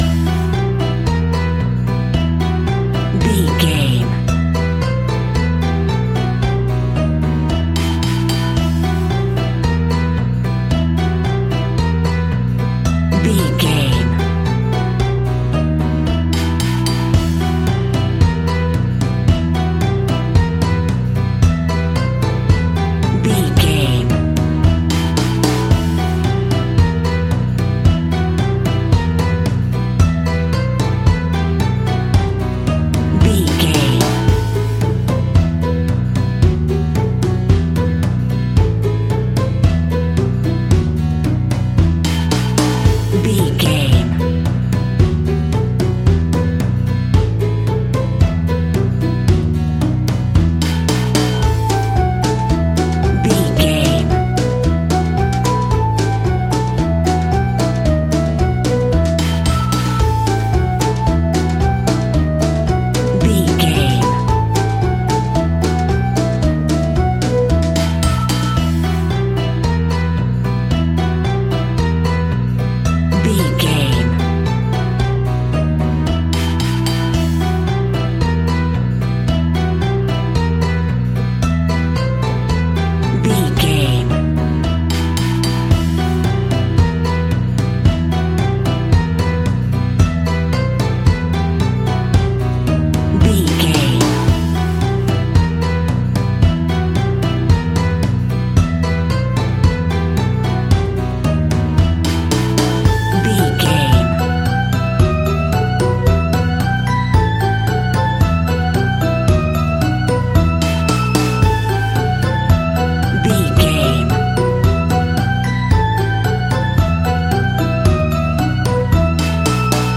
Aeolian/Minor
kids instrumentals
fun
childlike
cute
happy
kids piano